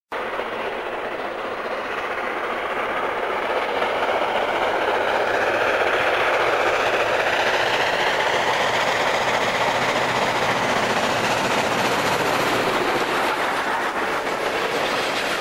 locomotive
steam
QJ 6952 appears around the curve as it storms up the steep and curved Nancha bank in Heilongjiang Province, North-East China.
There was another QJ at the rear banking tender first.
a recording of this train on Nancha Bank.